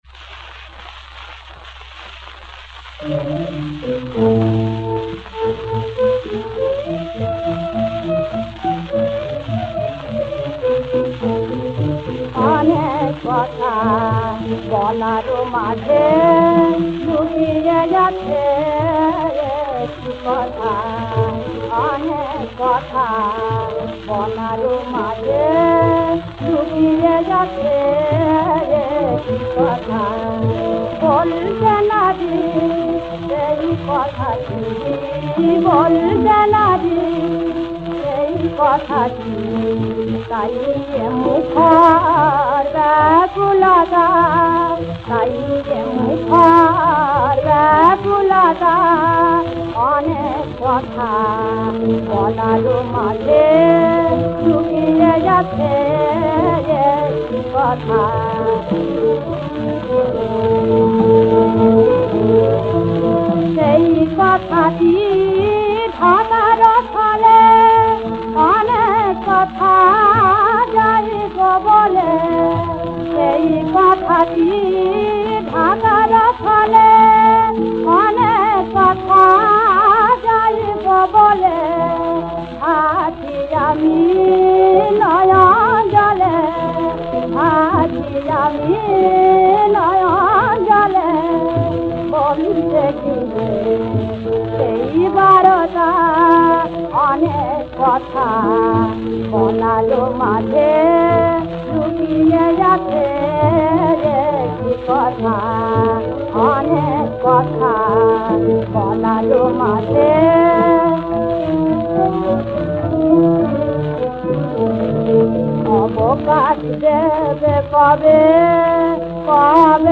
• তাল: কাহারবা